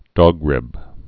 (dôgrĭb, dŏg-)